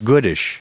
Prononciation du mot goodish en anglais (fichier audio)
Prononciation du mot : goodish